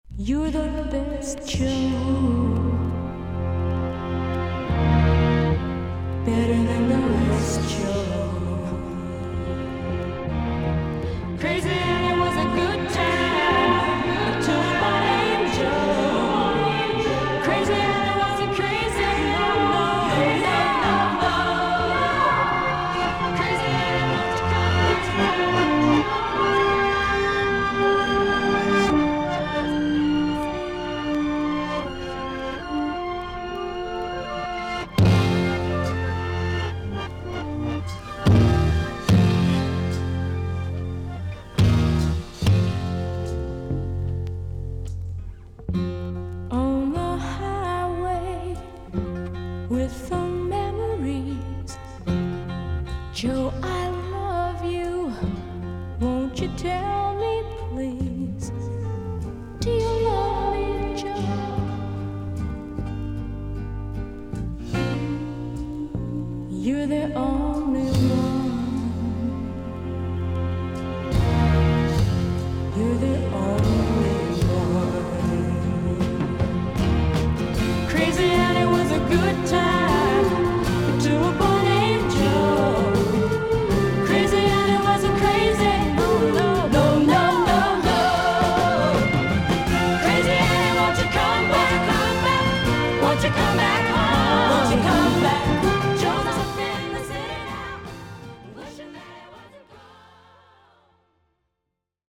少々軽いパチノイズの箇所あり。少々サーフィス・ノイズあり。クリアな音です。
女性シンガー/ソングライター。